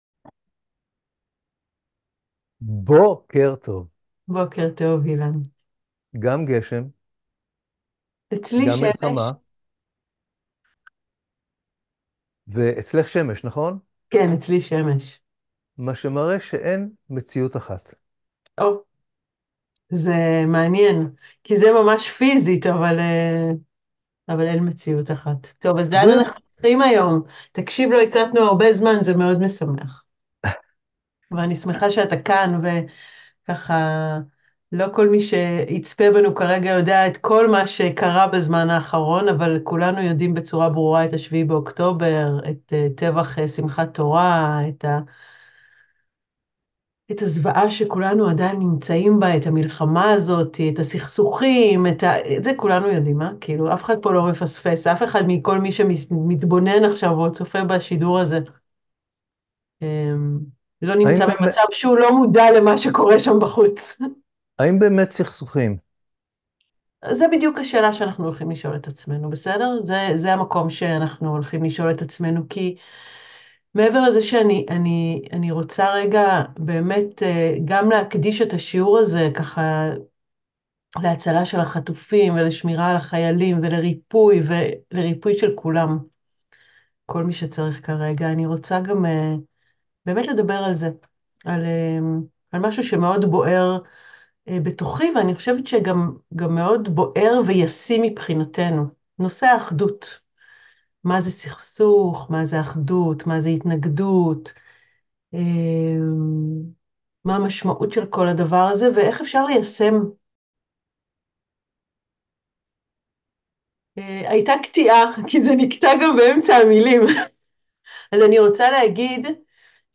שיעור מיוחד לימים קשים אלו